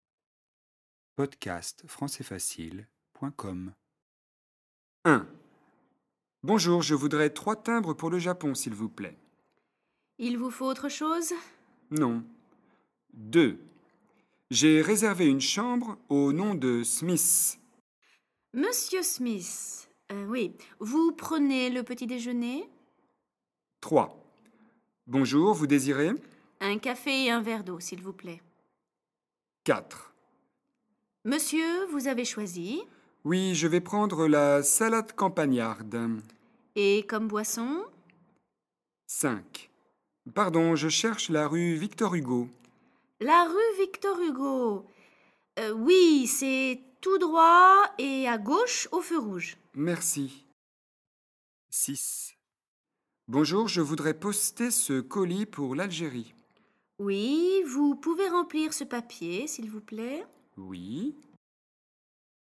Petit exercice de compréhension à partir d'un dialogue très court.